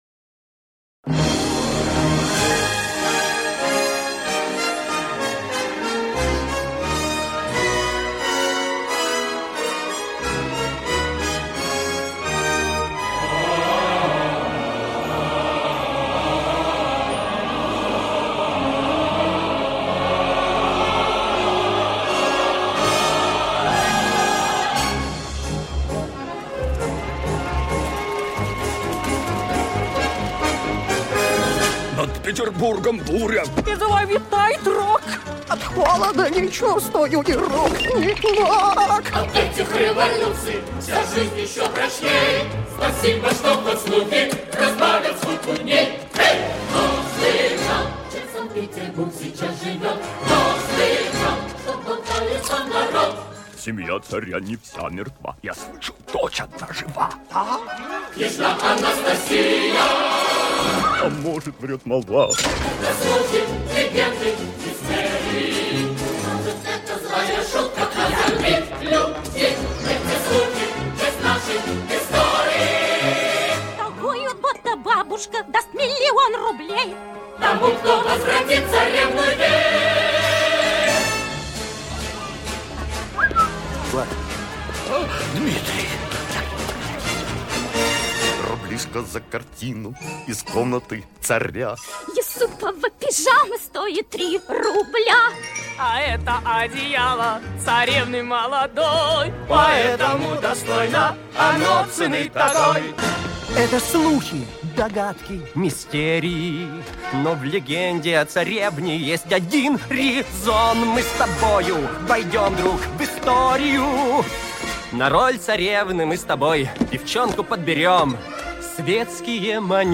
• Жанр: Детские песни
🎶 Детские песни / Песни из мультфильмов